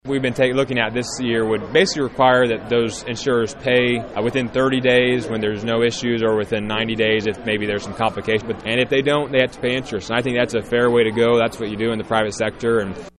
Still the state wants to see action and is willing to hold the companies feet to the fire.  State Representative Garrett Love explains.